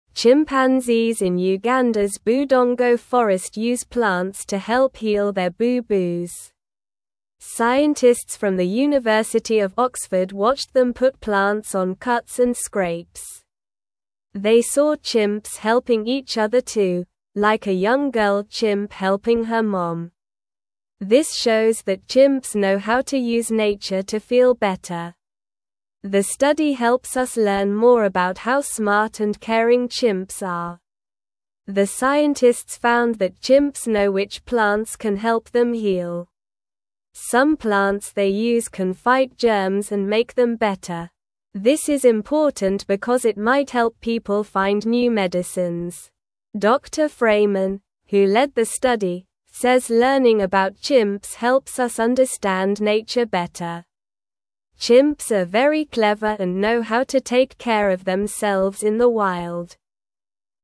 Slow
English-Newsroom-Beginner-SLOW-Reading-Chimps-Use-Plants-to-Heal-Their-Boo-Boos.mp3